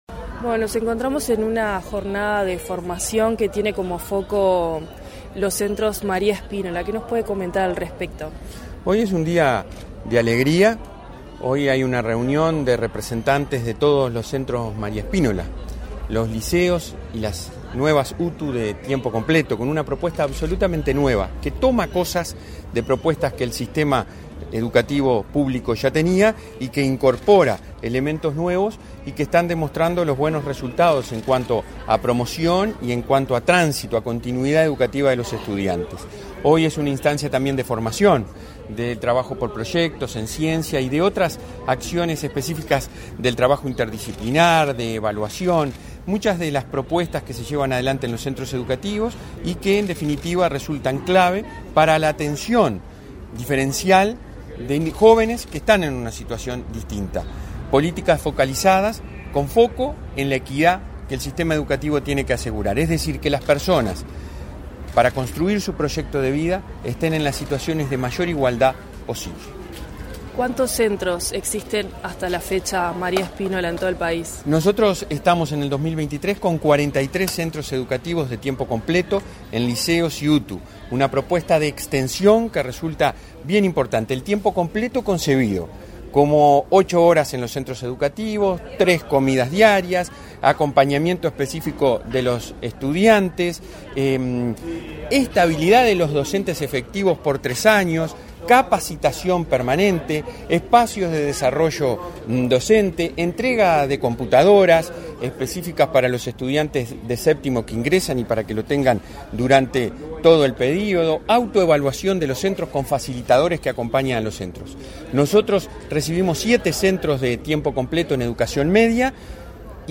Entrevista al presidente de la ANEP, Robert Silva
En la oportunidad Robert Silva realizó declaraciones a Comunicación Presidencial.